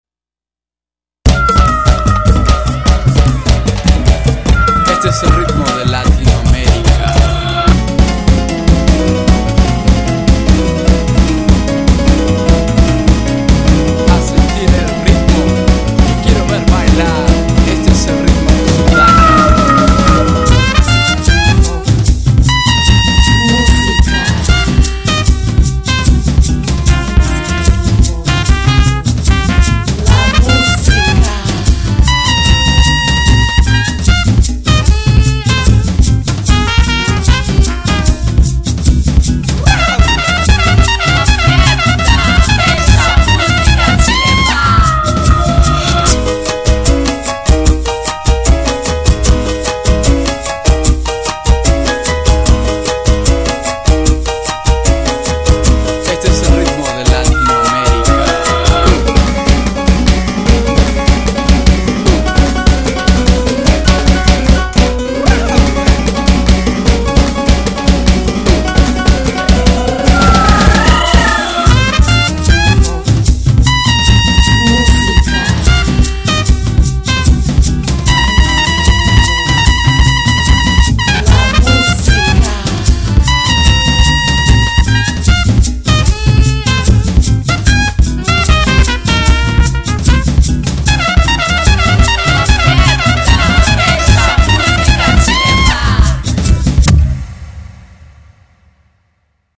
진짜 라틴 풍의 곡인데, 프로그래밍으로 쓴 곡인데도 곡이 좋네요.